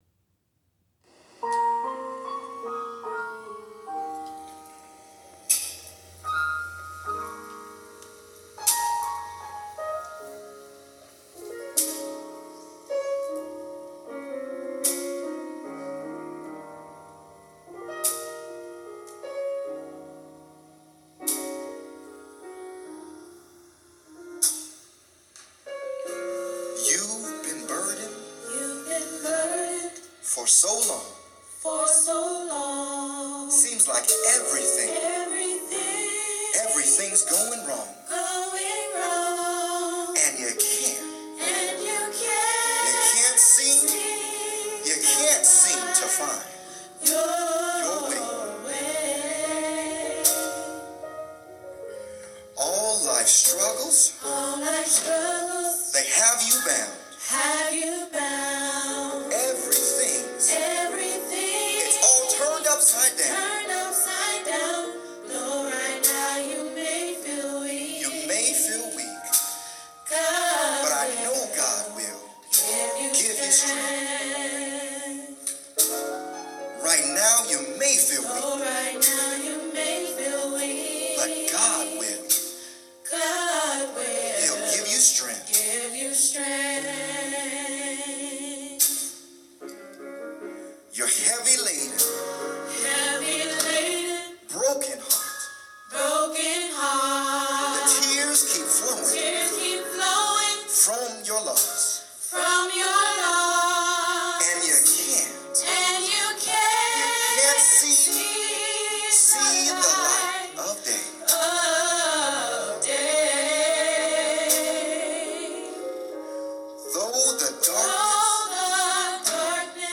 10Am Rehearsal
C# C# 4/4